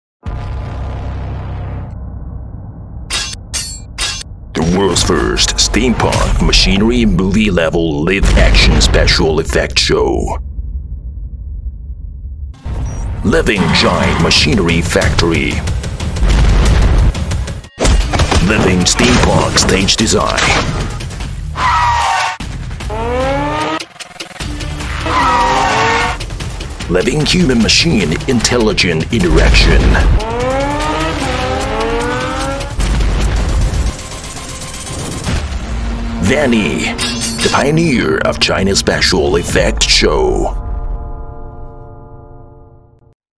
【专题】美式 中年音色 震撼片头开场
【专题】美式 中年音色 震撼片头开场.mp3